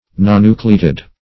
Nonnucleated \Non*nu"cle*a`ted\, a.
nonnucleated.mp3